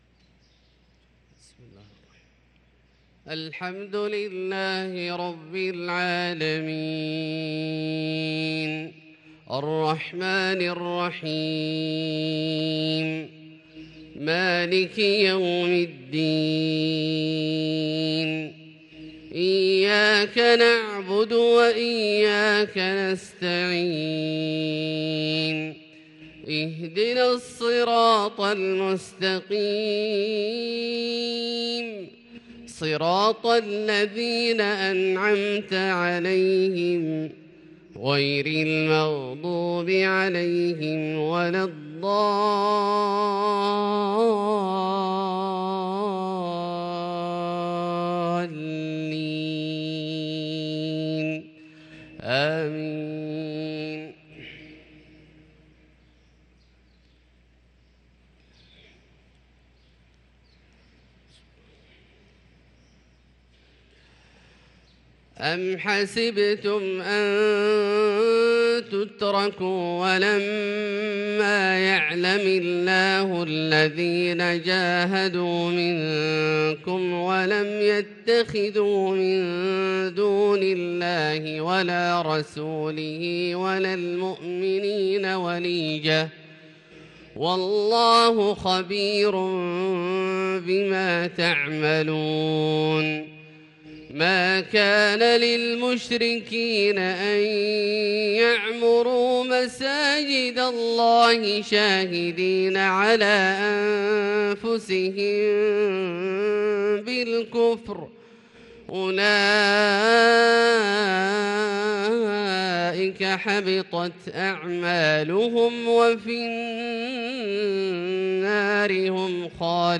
صلاة الفجر للقارئ عبدالله الجهني 19 صفر 1444 هـ
تِلَاوَات الْحَرَمَيْن .